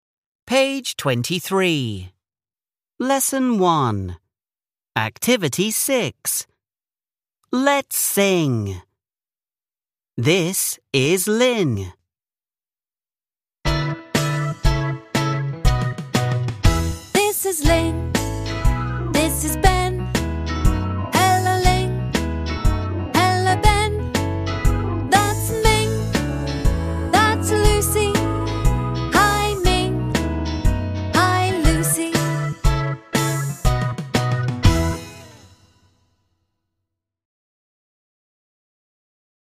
6 (trang 23 Tiếng Anh lớp 3 Global Success): Let’s sing  (Cùng hát)